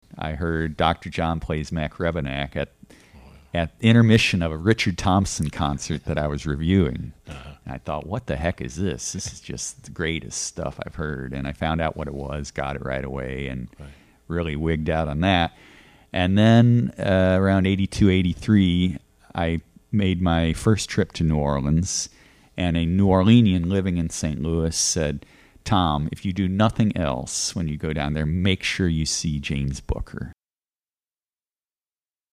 Audio outtake